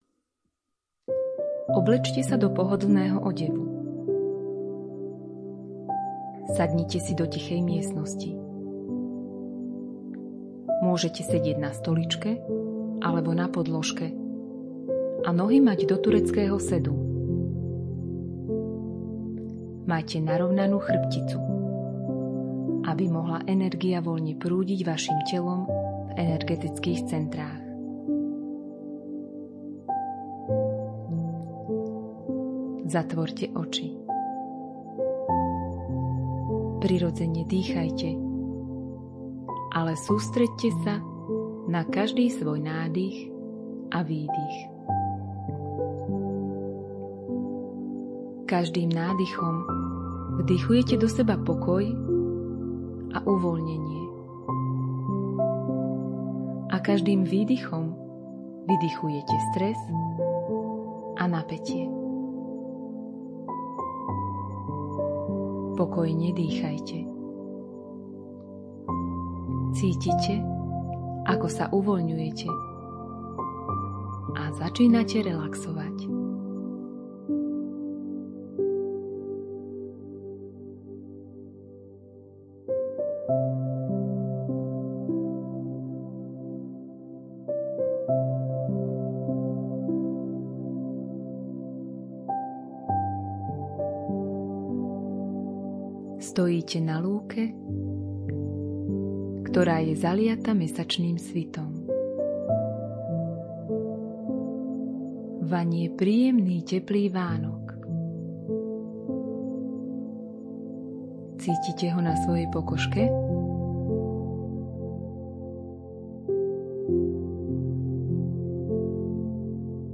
Meditačno-vizualizačné cvičenie Bohyňa v nás 3.
Meditacne-cvicenie-Bohyna-v-nas.mp3